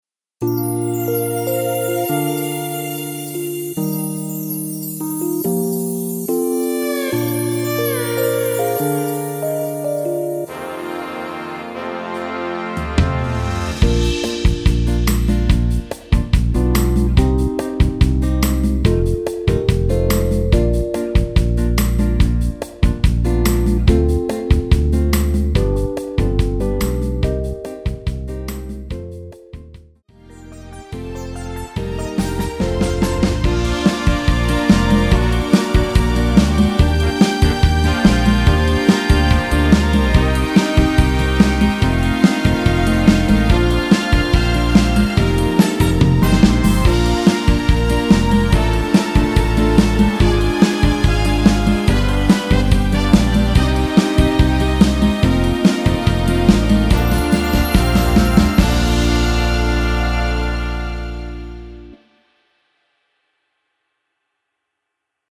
MR입니다.
Bb
앞부분30초, 뒷부분30초씩 편집해서 올려 드리고 있습니다.
중간에 음이 끈어지고 다시 나오는 이유는